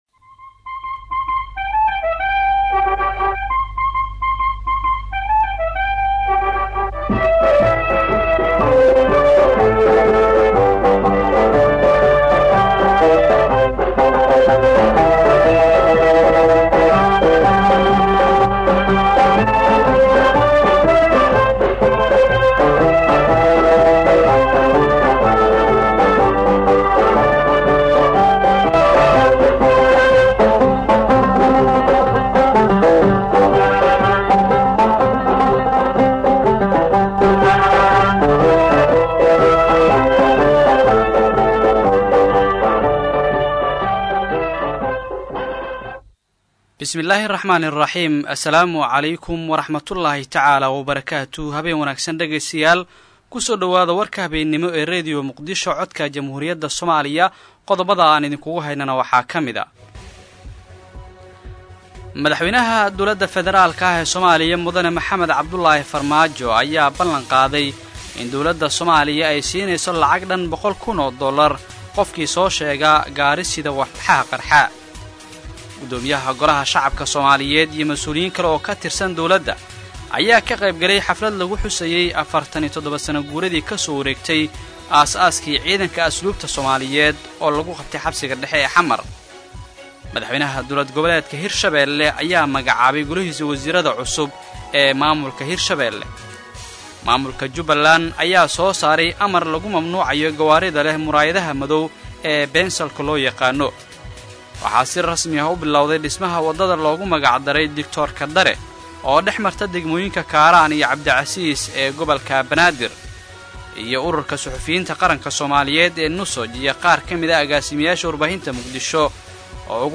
Dhageyso Warka Habeen ee Radio Muqdisho